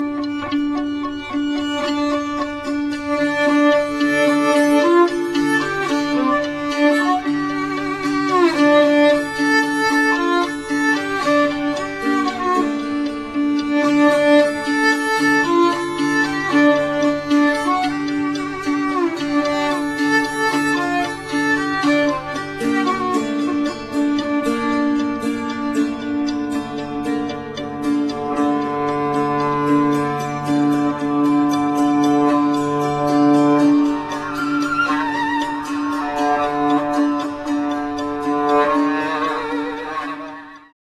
wiolonczela cello
cymbały dulcimer
nyckelharpa